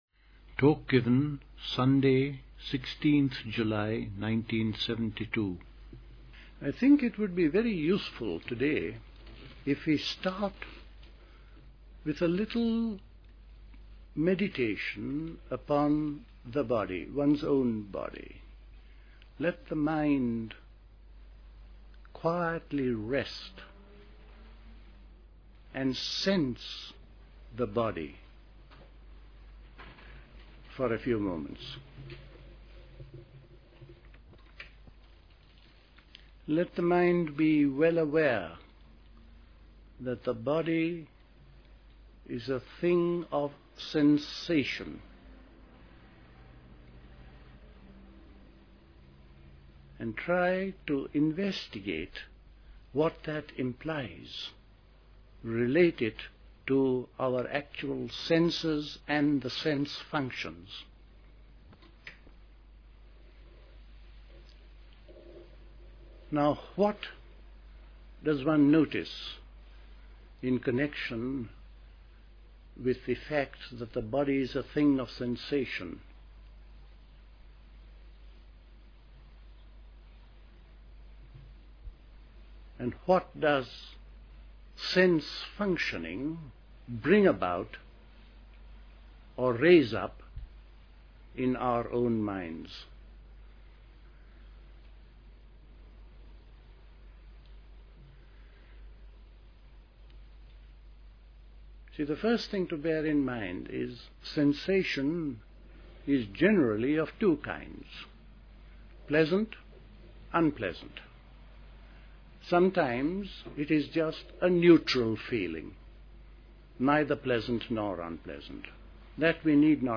A talk
at Dilkusha, Forest Hill, London on 16th July 1972